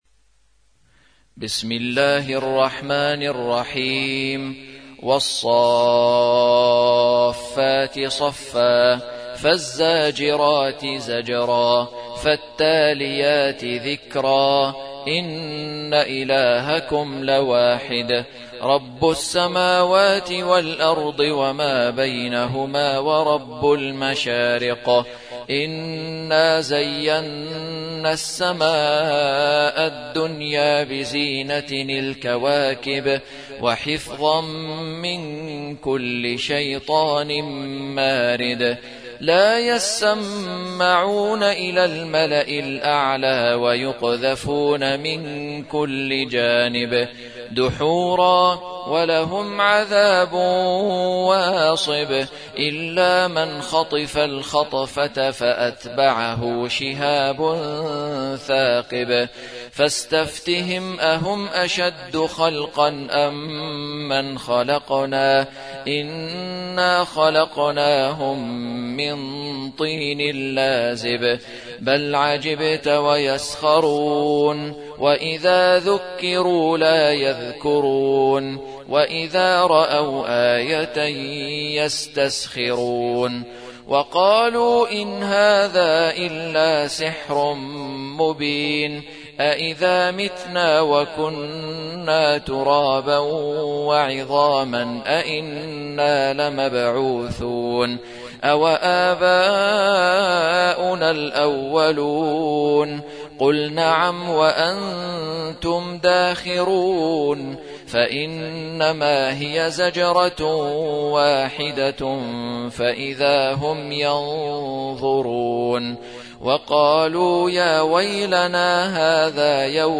37. سورة الصافات / القارئ